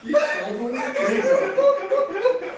risada-_audiotrimmer.mp3